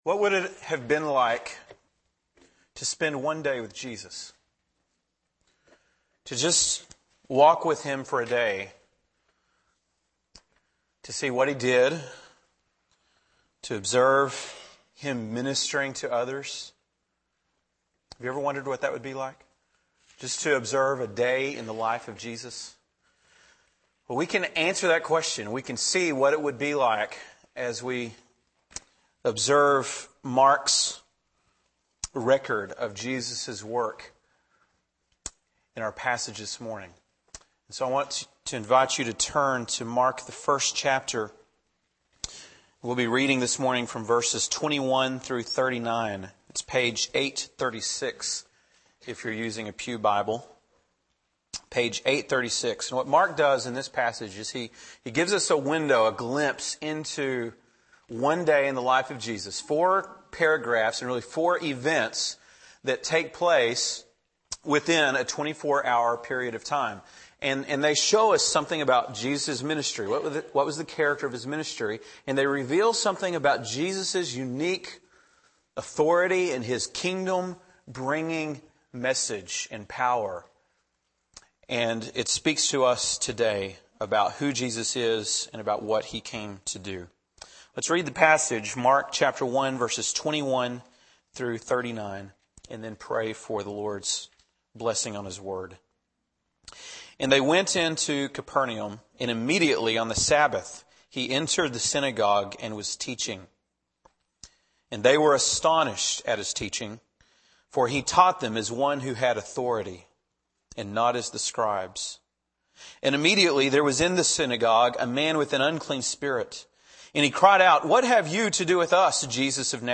March 25, 2007 (Sunday Morning)